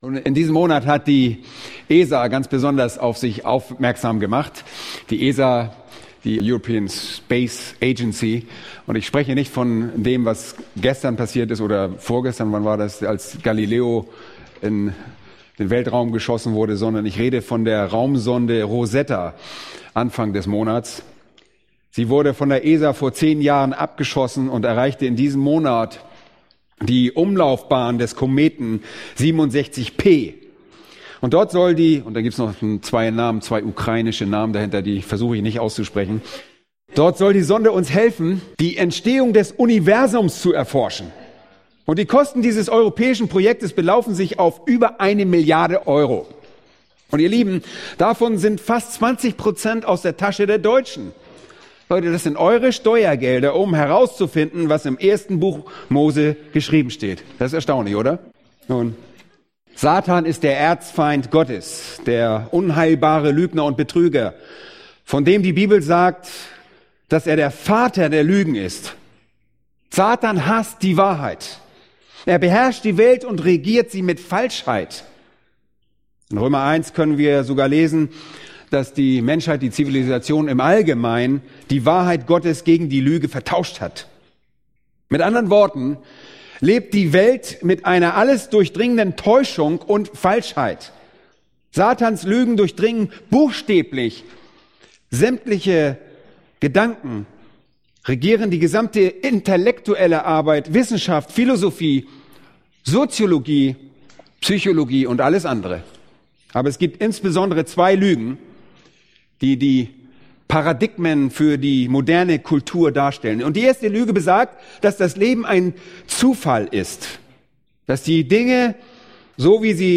Eine predigt aus der serie "Weitere Predigten."